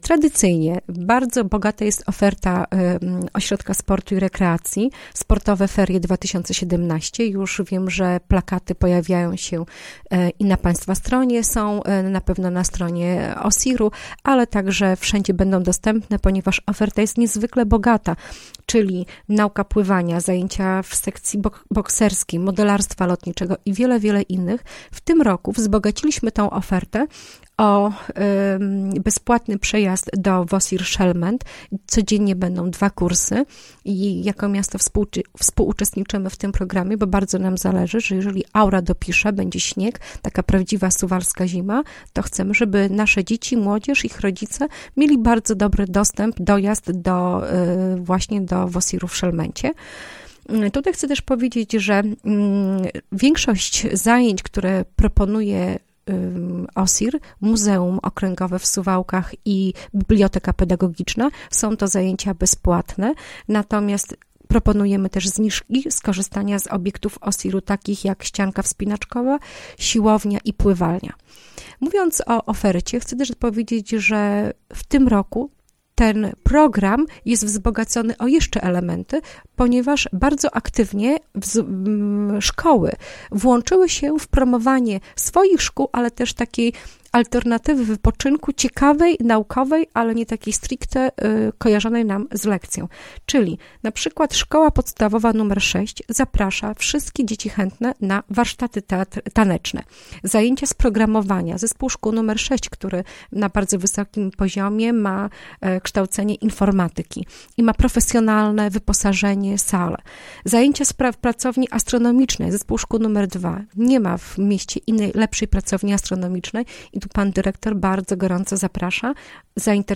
O tym jak można będzie spędzić ferie w Suwałkach mówiła w piątek (13.01) w Radiu 5 Ewa Sidorek, zastępca Prezydenta Suwałk.
Ewa-Sidorek-zastępca-Prezydenta-Suwałk.mp3